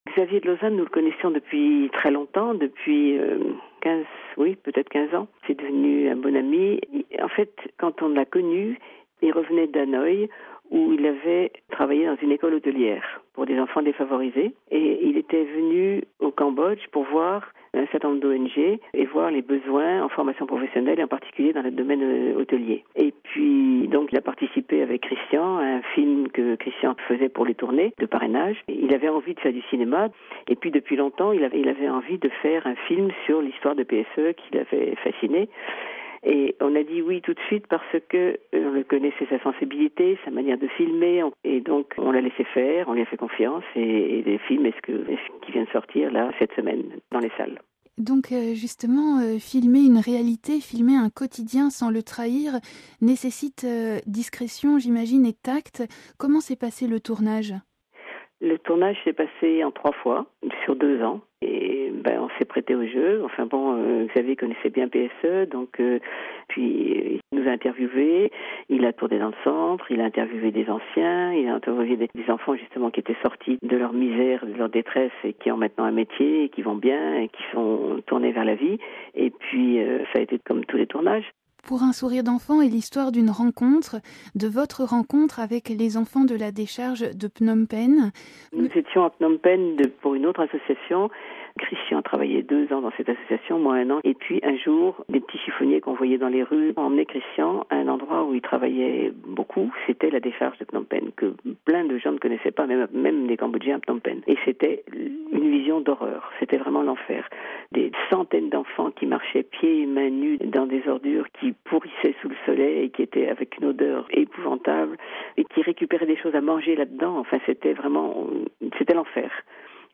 (RV) Entretien - Les Pépites est sorti le 5 octobre dans les salles de cinéma.